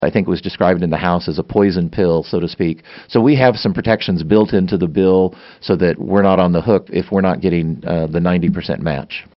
Lt. Gov. Lynn Rogers in-studio for KMAN's In Focus, Wednesday, April 3, 2019.